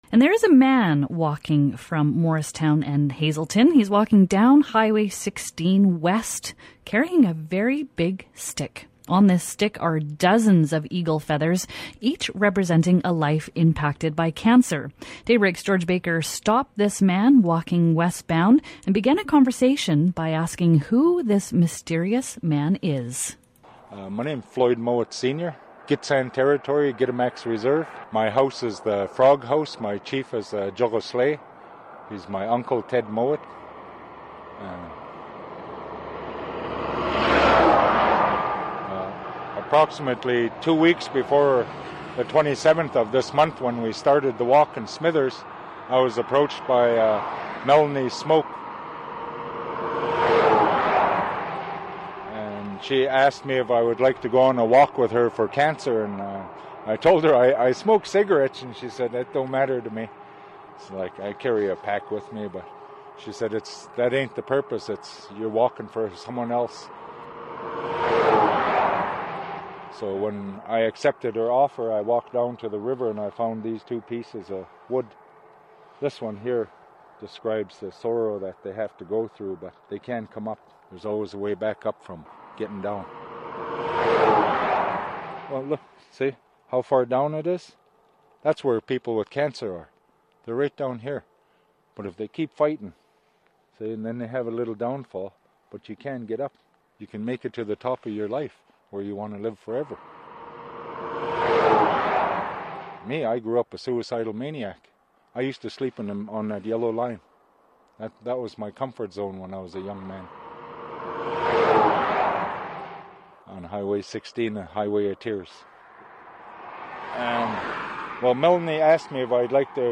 Listen as he starts a conversation asking who this mysterious man is.